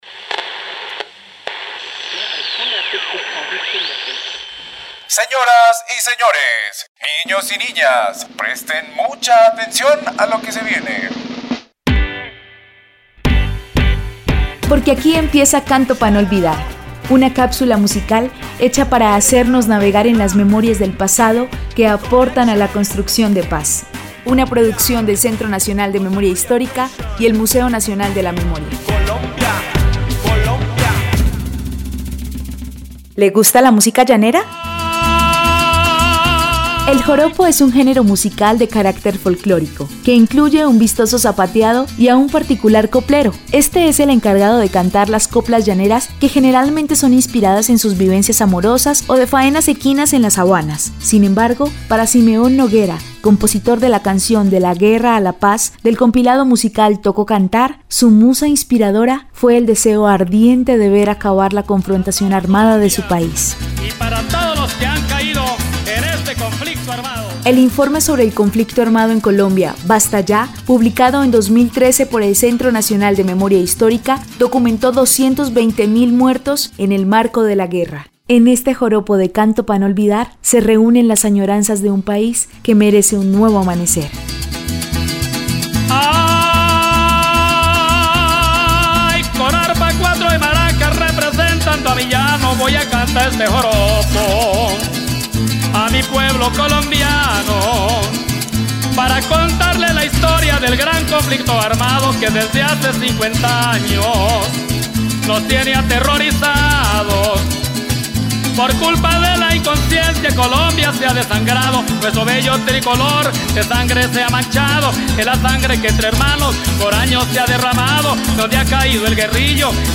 compositor y cantante.
Hace referencia al podcast Tocó cantar (Travesía contra el olvido) De la guerra a la paz (Joropo).